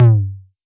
Index of /musicradar/retro-drum-machine-samples/Drums Hits/WEM Copicat
RDM_Copicat_SY1-Tom02.wav